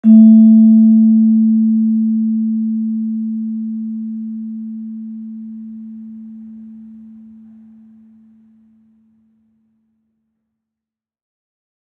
Gender-2-A4-f.wav